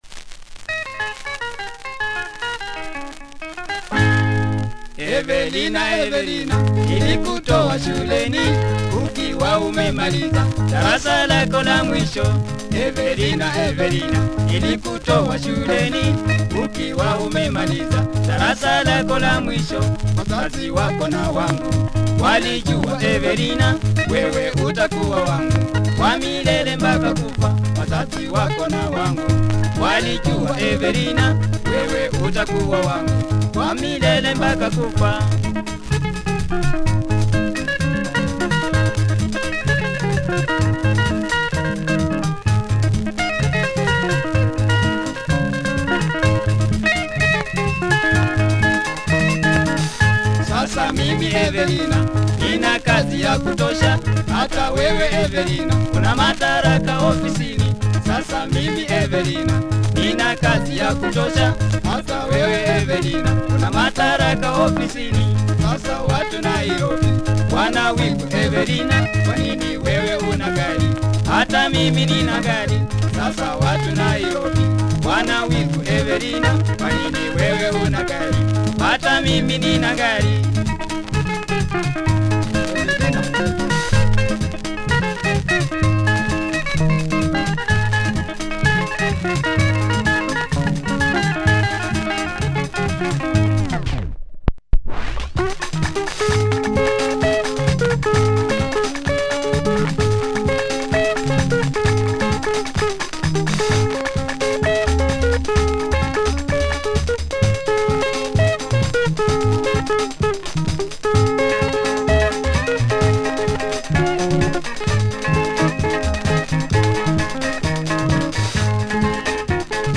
Lingala track